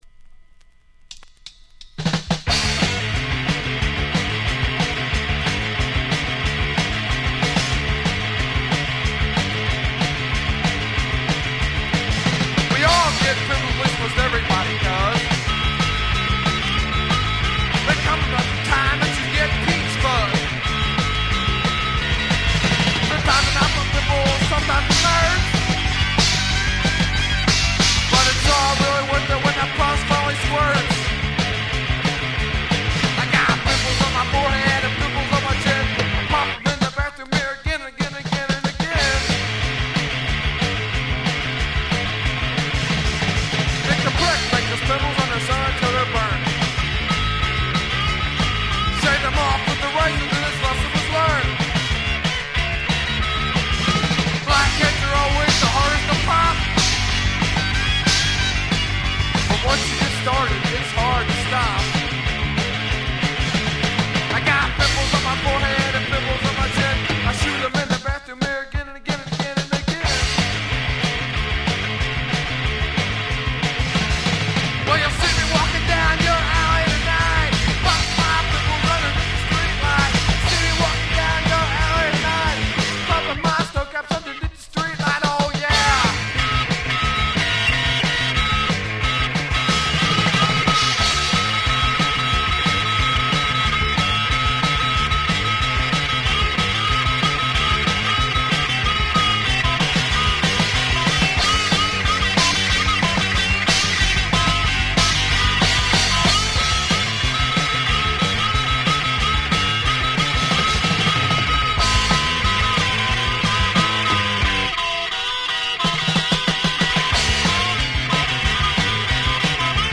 Garage/Roots/Slop-Rock bands